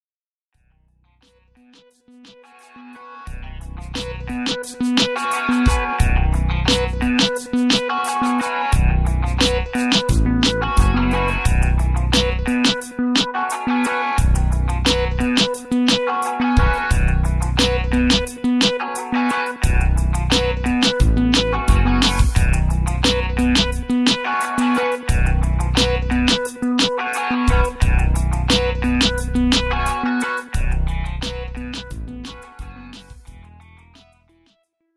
This is an instrumental backing track cover.
• Key – B
• With Backing Vocals
• No Fade